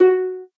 noteblock_harp.wav